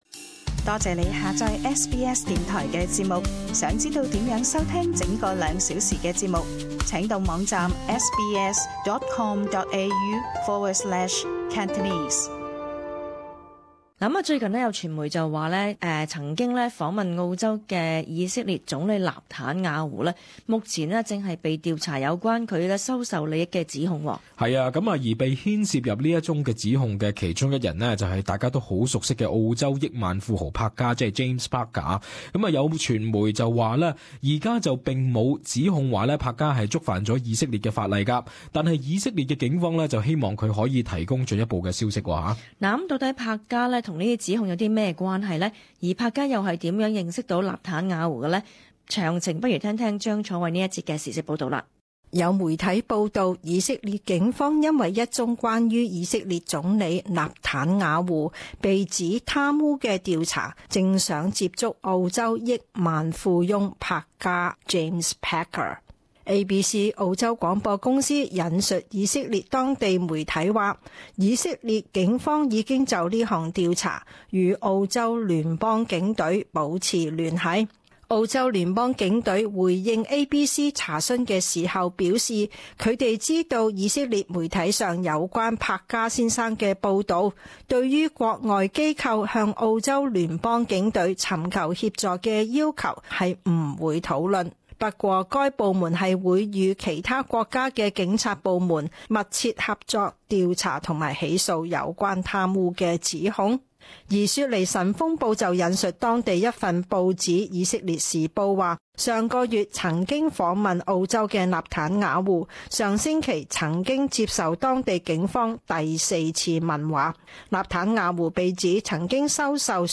【時事報導】澳富豪柏嘉捲入納坦雅胡收受利益案